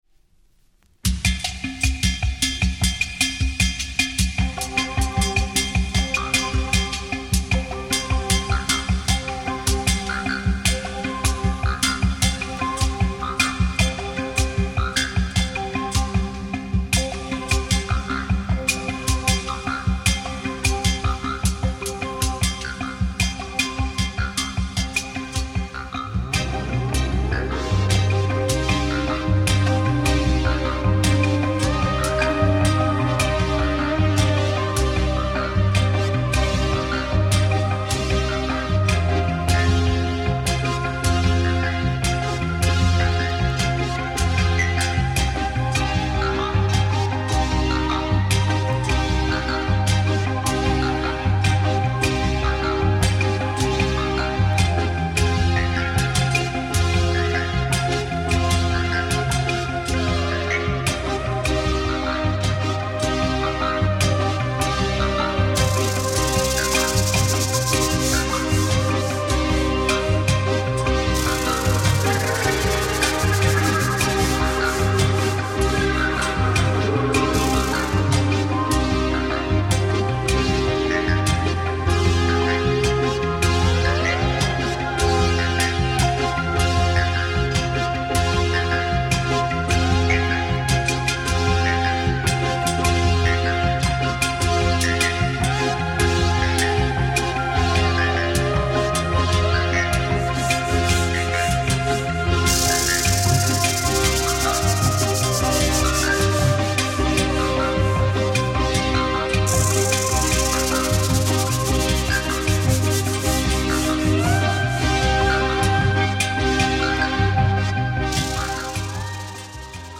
Italian cosmic minimal electro
Italian Cosmic synthé intrumental sound, rare German issue